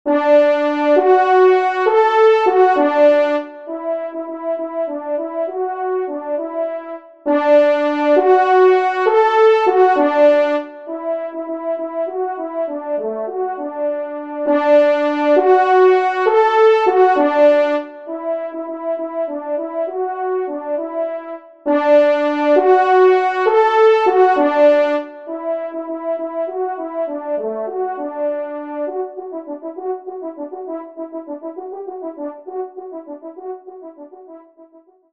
1e Trompe
Millescamps-AFT-10-Indiana_TRP-1_EXT.mp3